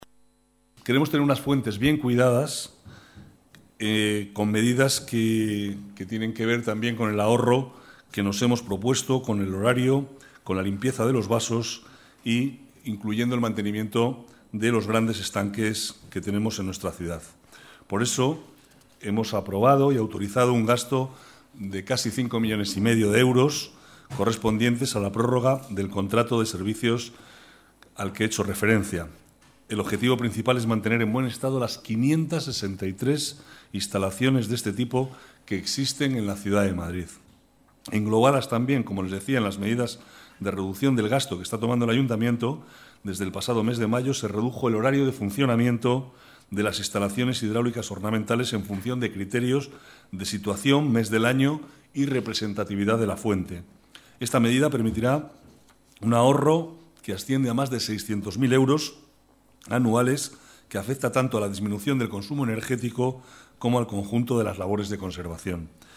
Nueva ventana:Declaraciones vicealcalde, Manuel Cobo: mantenimiento de fuentes ornamentales